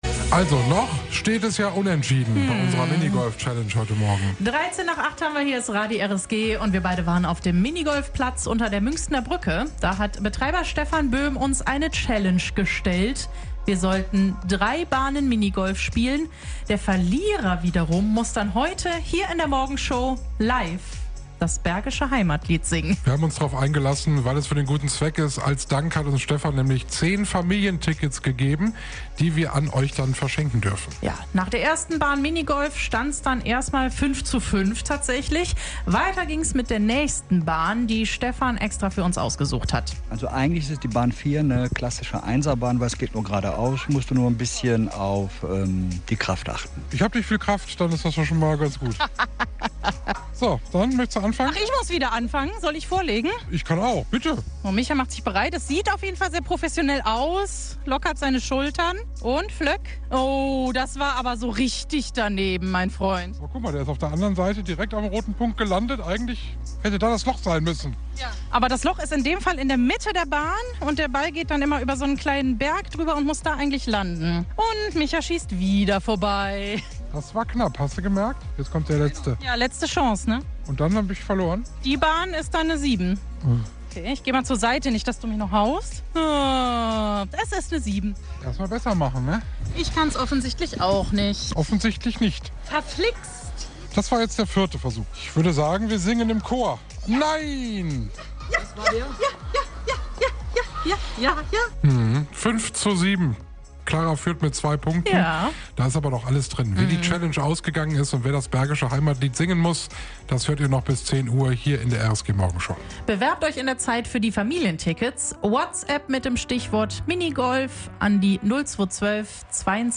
Radio RSG vor Ort: Minigolf-Challenge zum Jubiläum